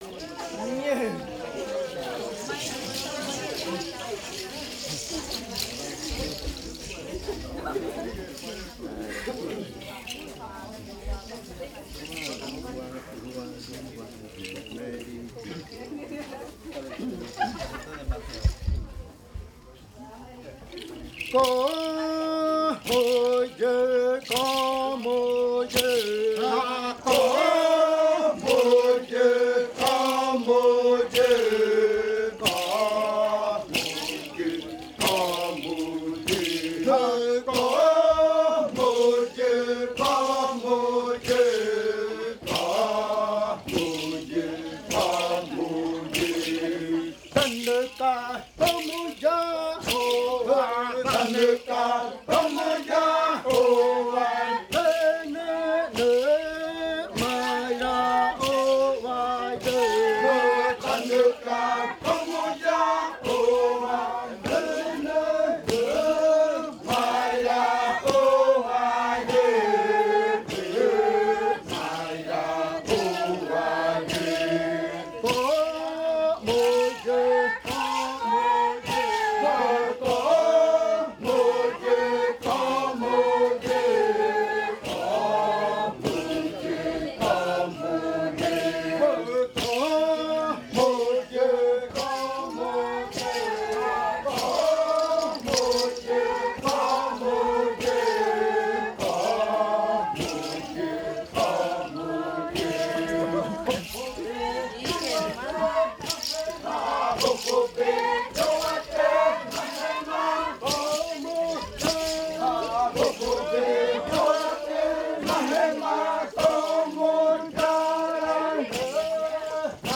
Canto después de medianoche de la variante muinakɨ
Leticia, Amazonas
con el grupo de cantores bailando en la Casa Hija Eetane. Este canto fue interpretado en el baile de clausura de la Cátedra de lenguas "La lengua es espíritu" de la UNAL Sede Amazonia.